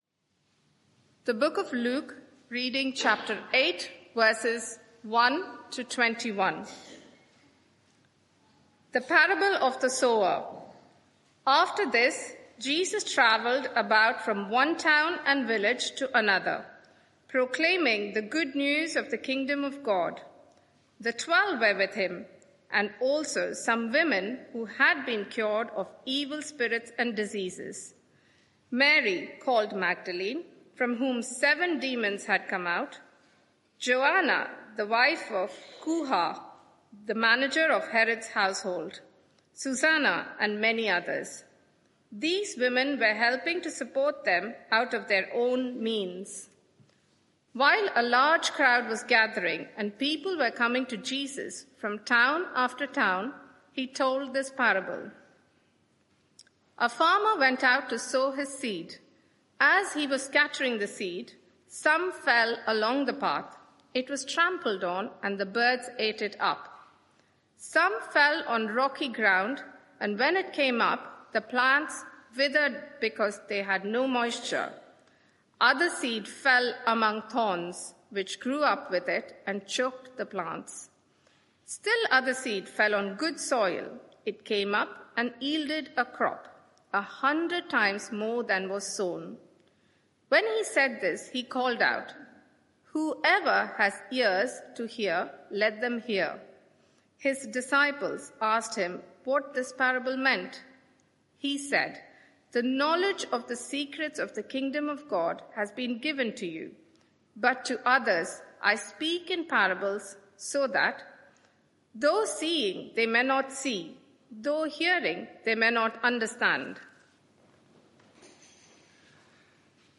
Media for 11am Service on Sun 30th Mar 2025 11:00 Speaker
Passage: Luke 8:1-21 Series: What a Saviour! Theme: Luke 8:1-21 Sermon (audio) Search the media library There are recordings here going back several years.